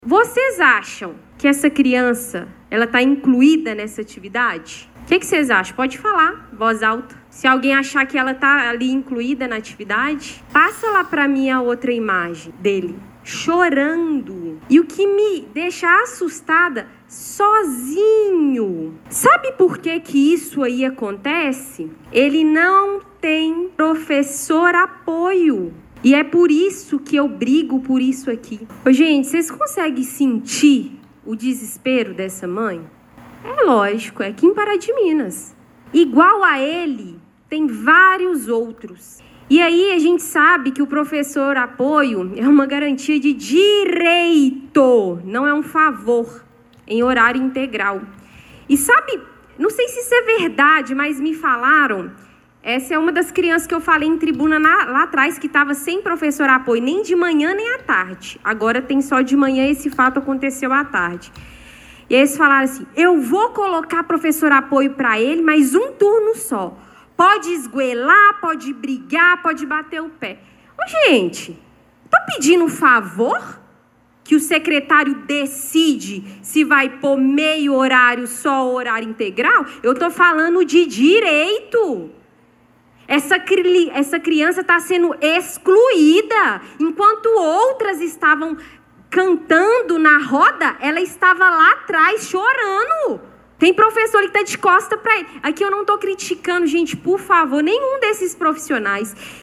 A reunião ordinária da Câmara Municipal de Pará de Minas, realizada ontem, 24 de março, foi marcada por debates e um forte desabafo com denúncias graves sobre a situação da educação inclusiva na cidade.